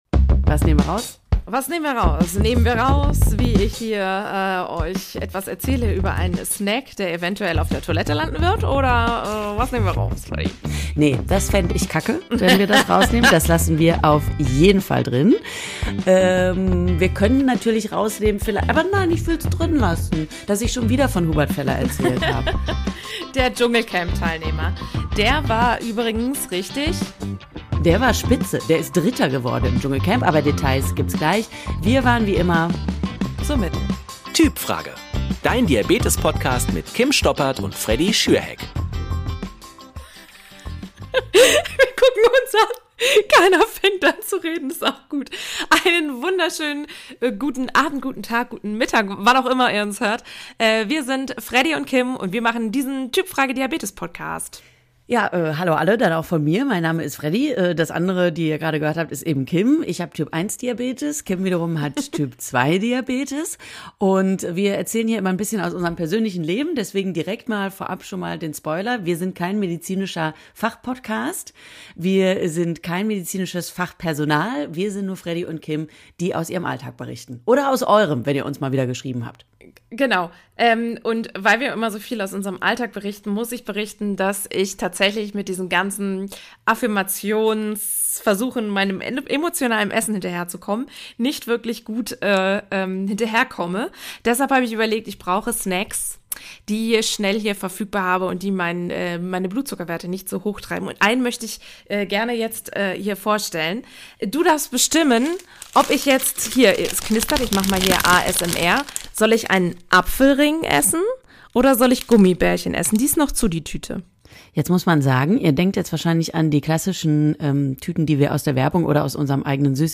Schmatzen inklusive. Sorry dafür.